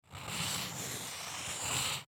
Звуки маркера
Зачеркиваем надпись на картоне маркером